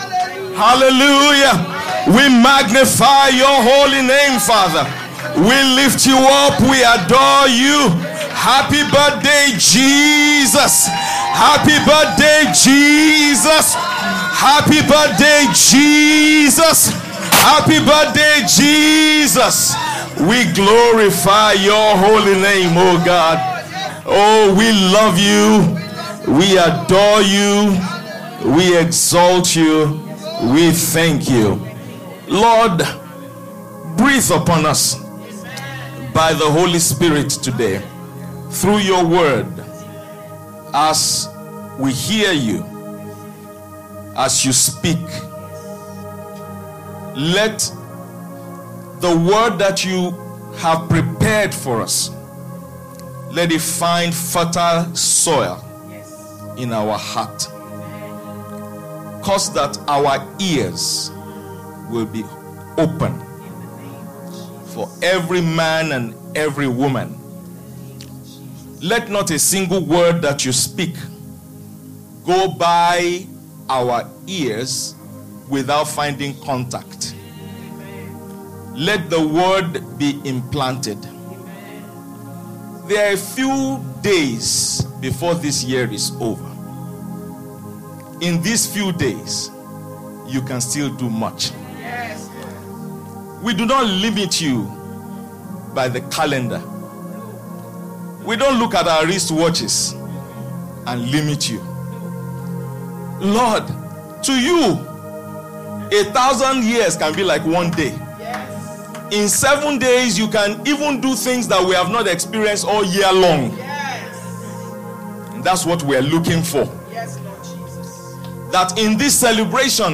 Sermons – Abundant Life International Church